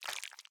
Minecraft Version Minecraft Version snapshot Latest Release | Latest Snapshot snapshot / assets / minecraft / sounds / block / honeyblock / slide4.ogg Compare With Compare With Latest Release | Latest Snapshot
slide4.ogg